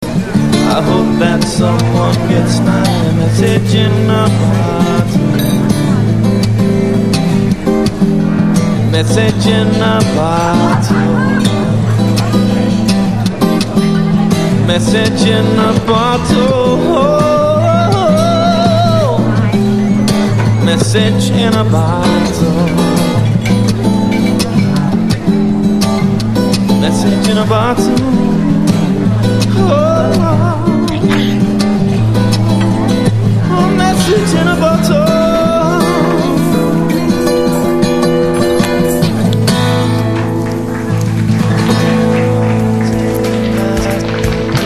Disc Jockey  - Guitar Vocal   - Keyboard Vocal  -  Classical Guitar  - Classic Piano - Sax